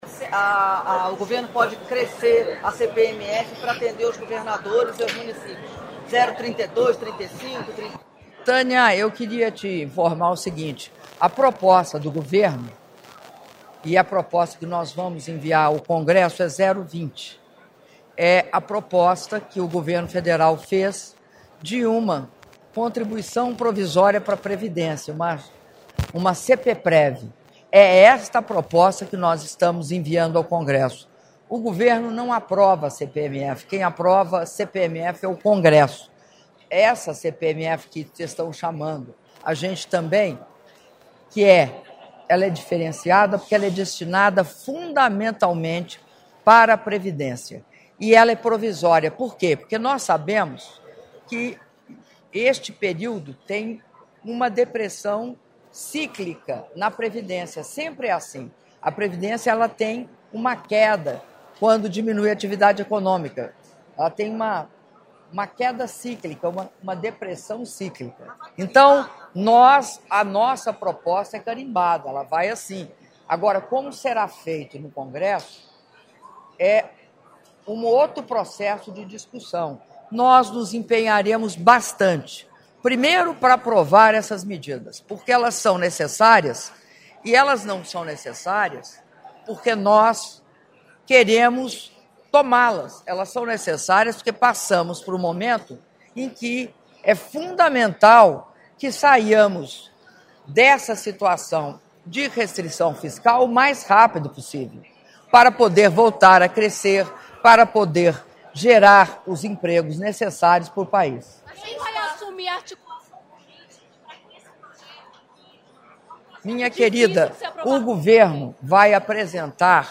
Áudio da entrevista coletiva concedida pela Presidenta da República, Dilma Rousseff, após cerimônia de entrega do XXVIII Prêmio Jovem Cientista – Segurança Alimentar e Nutricional - Brasília/DF (4min39s) — Biblioteca